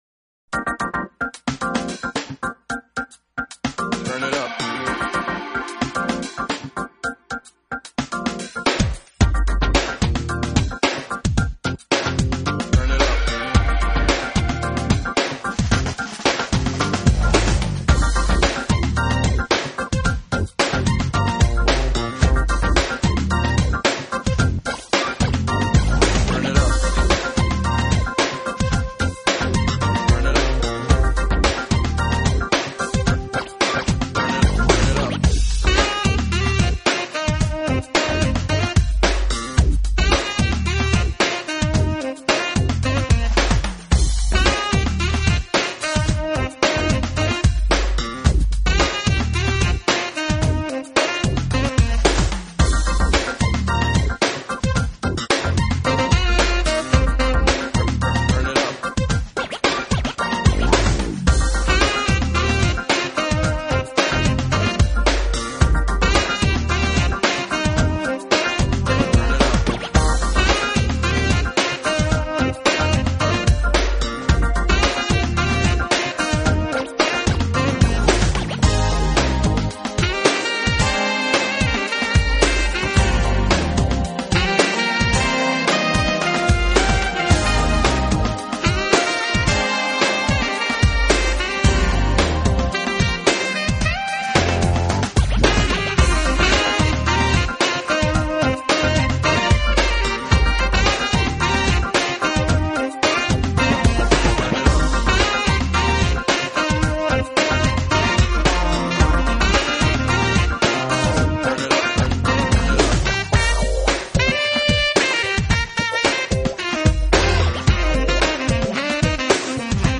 【爵士萨克斯】
音乐类型：Smooth Jazz
是深沉而平静，轻柔而忧伤，奇妙而富有感情。